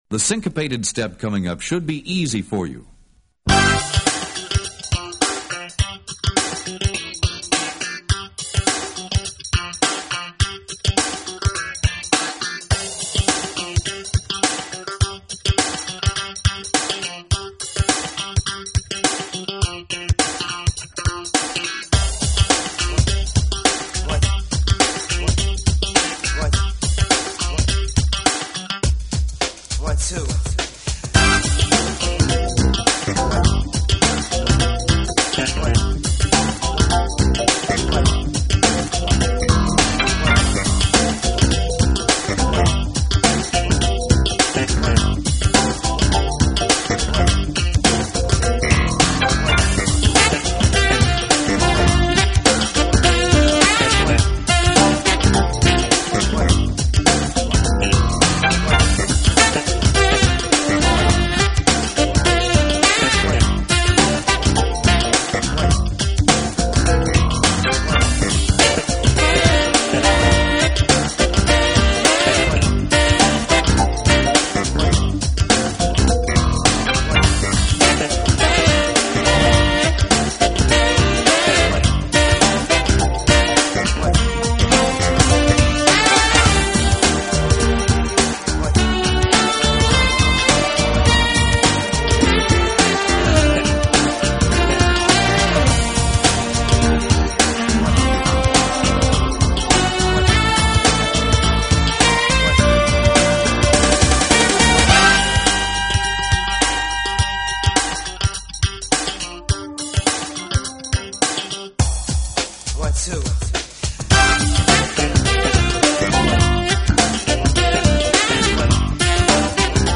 saxophonist/composer